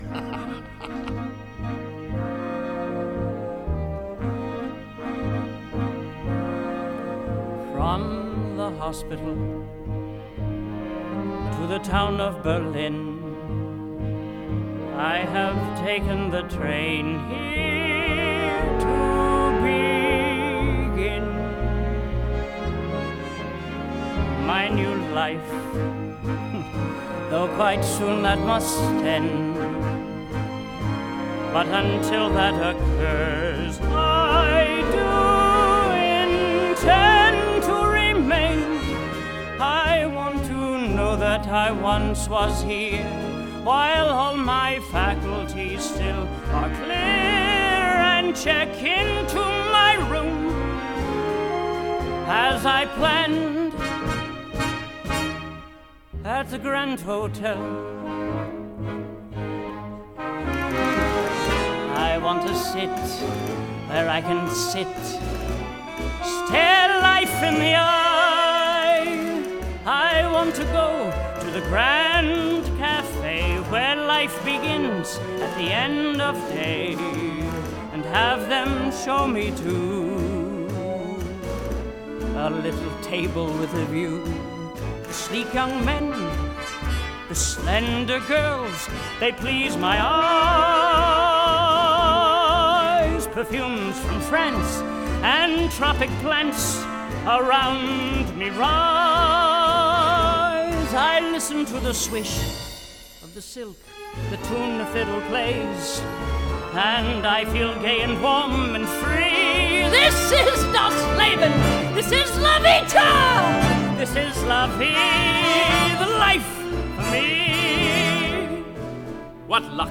1989/1958   Genre: Musical   Artist